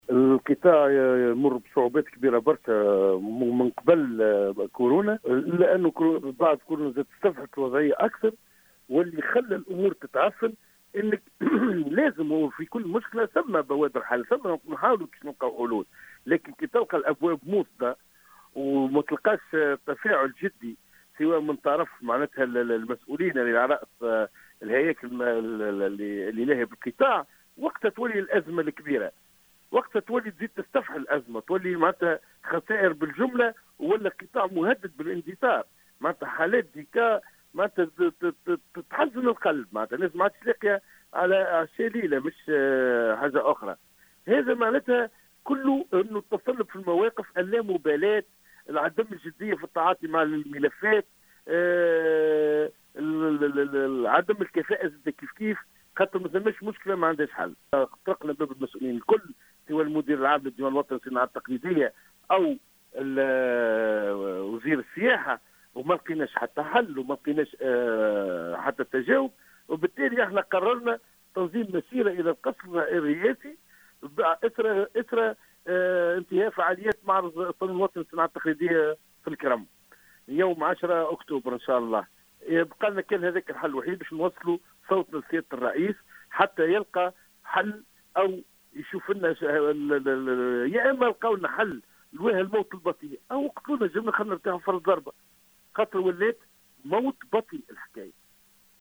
في تصريح هاتفي للجوهرة أف-أم